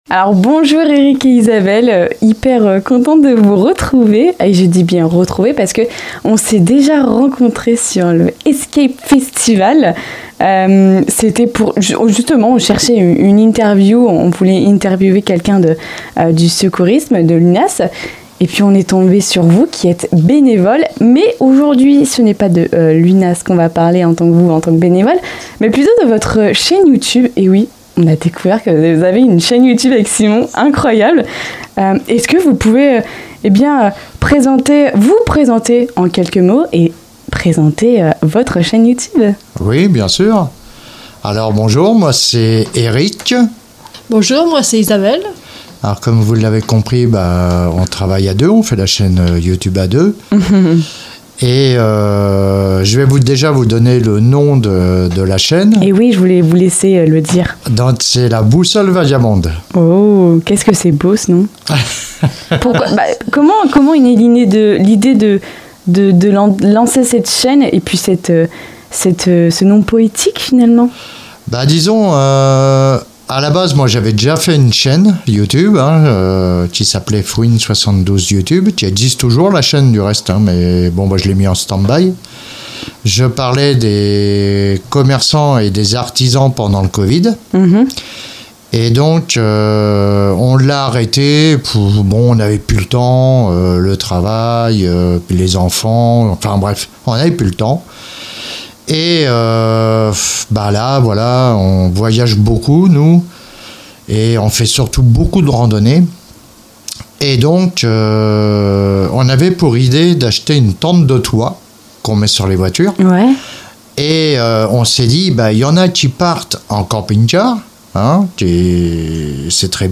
Lundi 25 août, nous avons reçu dans les locaux de Radio Pulse